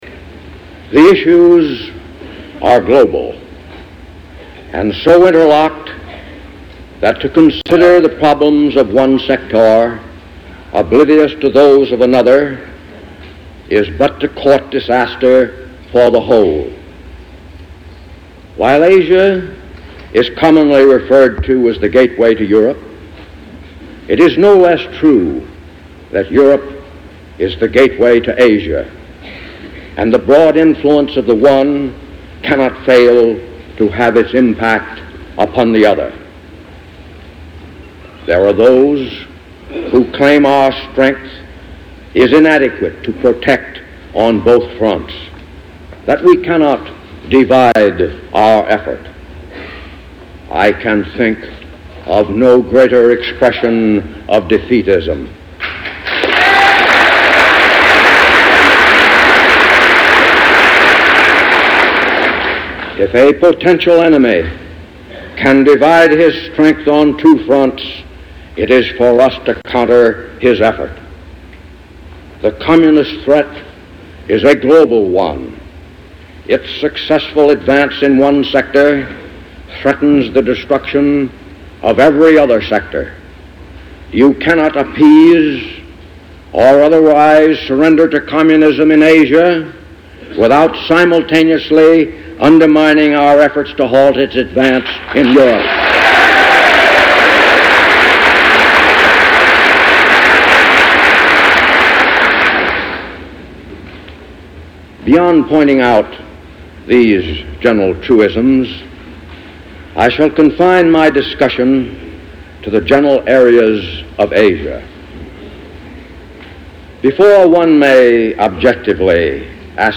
Tags: Historical General Douglas Mac Arthur Worl War II Farewell Address